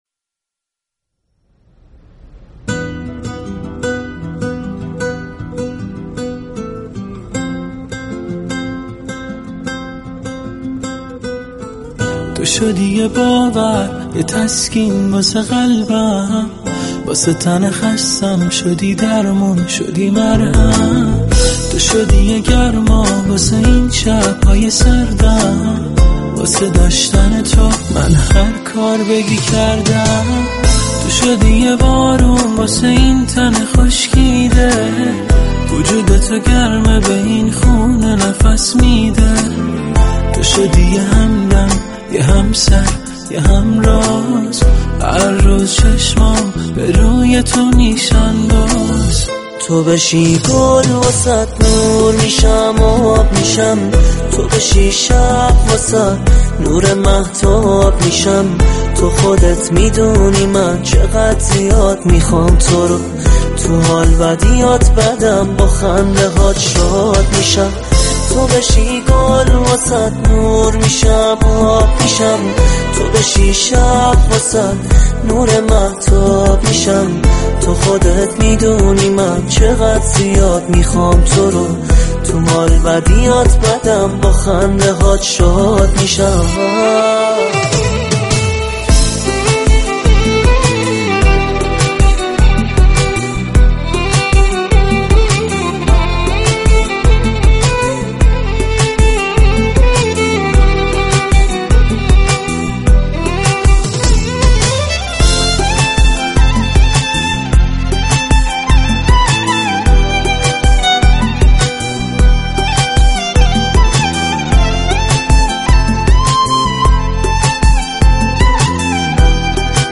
در نقد آهنگ، مخاطبان برنامه نیز در كنار منتقدان و در تماس با برنامه به نقد شفاهی آهنگ‌ها می‌پردازند